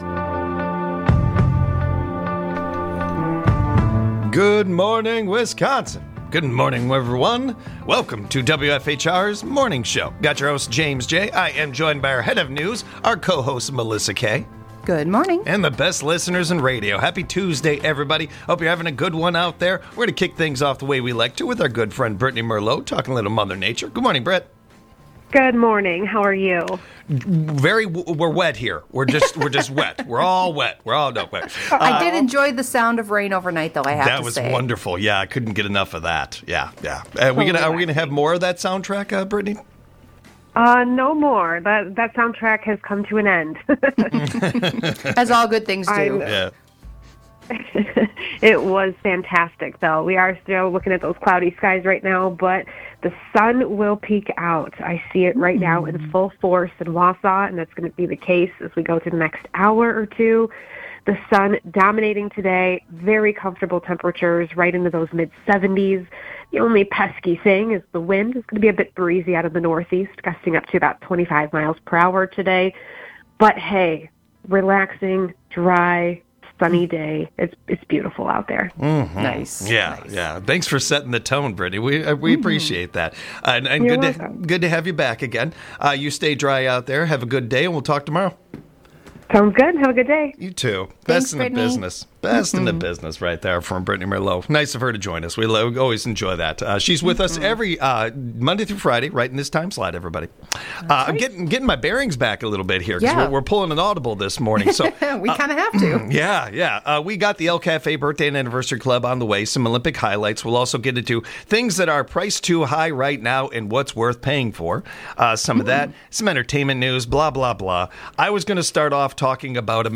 This Mornings with WFHR starts with a bang as our co-hosts get breaking news as the show begins!